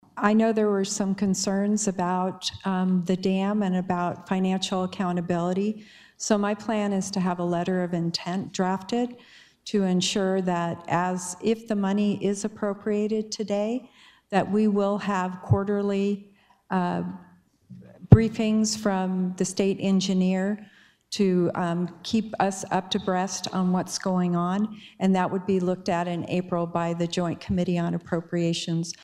SD House: